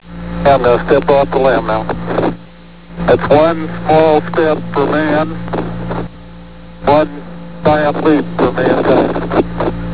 (Neil Armstrong's famed first line from the Moon.)